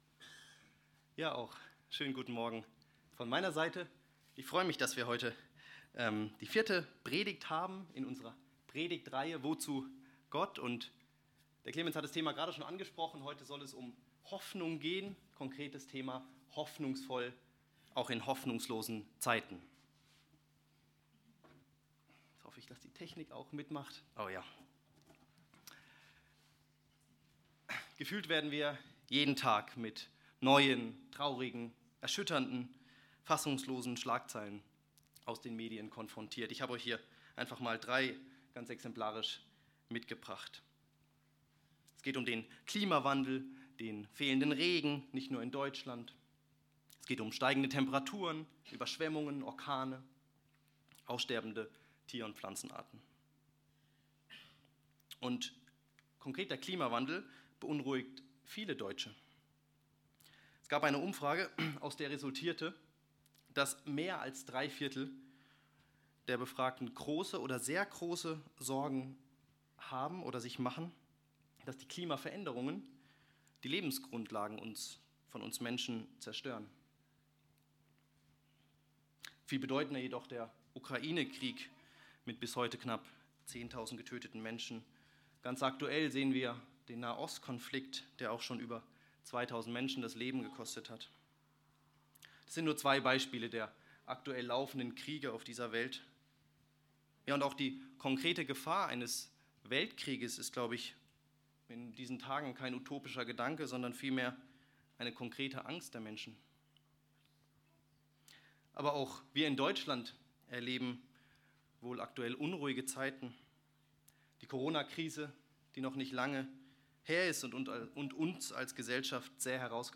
Aus der Predigtreihe: "Wozu Gott"